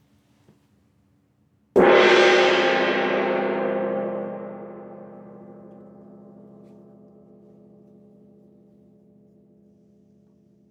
petit_1coup_moy.wav